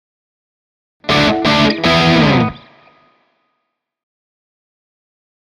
Rock Guitar - Final Chords Version 1